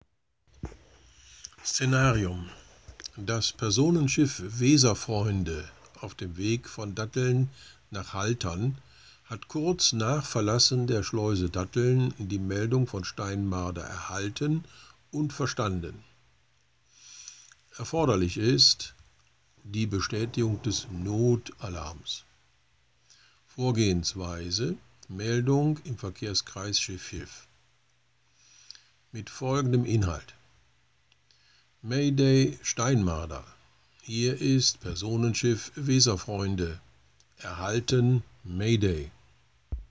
UBI - Sprechfunkbeispiele
Vor den eigentlichen Funksprüchen, gleichgültig ob Not-, Dringlichkeits- oder Sicherheitsverkehr, wird das zugehörige Szenarium dargestellt sowie die erforderlichen Maßnahmen und das Vorgehen benannt, um ein größeres Verständniss für den Funkspruch zu erreichen.